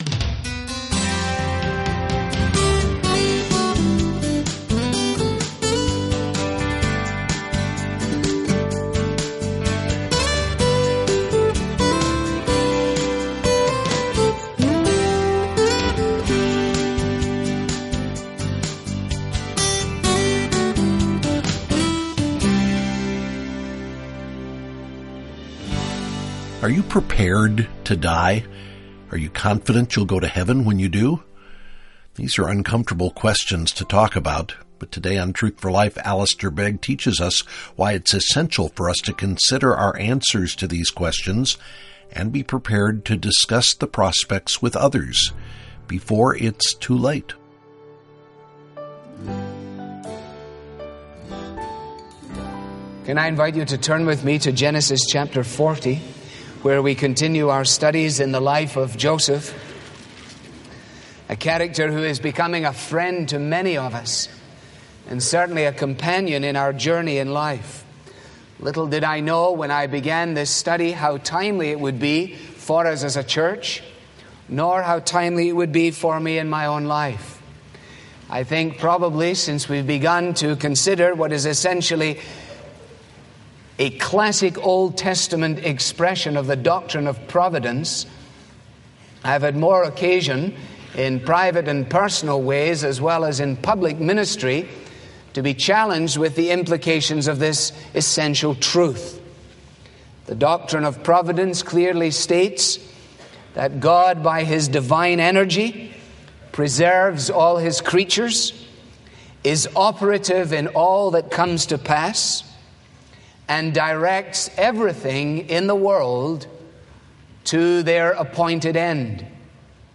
• This program is part of the sermon ‘The Hand of God, Volume 1’ • Learn more about our current resource, request your copy with a donation of any amount.